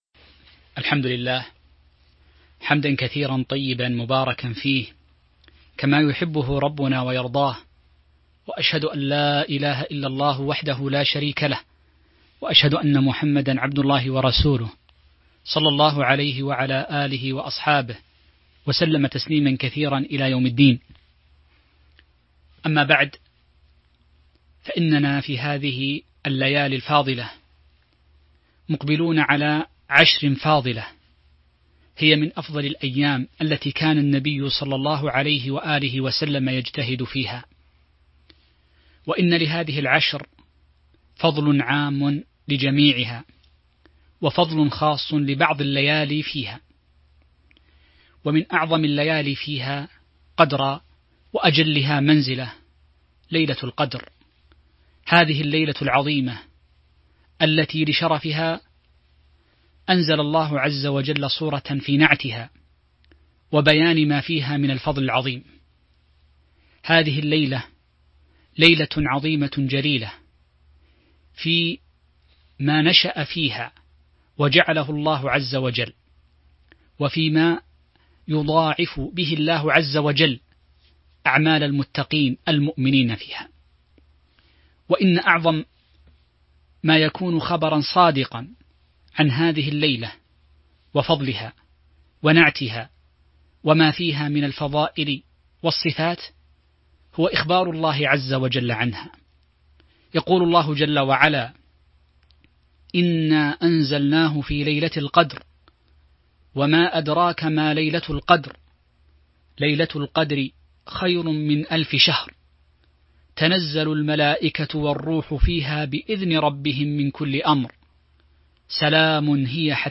تاريخ النشر ١٩ رمضان ١٤٤٢ هـ المكان: المسجد النبوي الشيخ